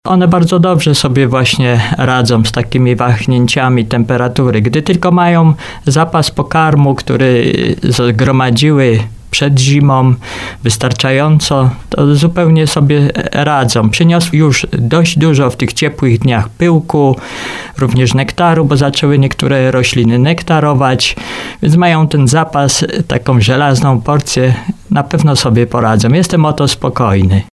10pszczelarz.mp3